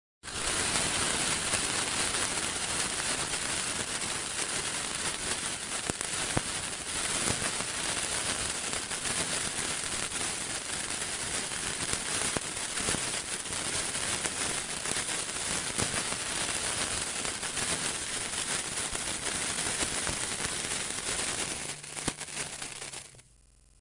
Яркое шипение, веселый треск и фейерверк искр — эти знаменитые праздничные звуки создадут волшебную атмосферу Нового года, дня рождения или другого торжества.
Бенгальский огонь для праздника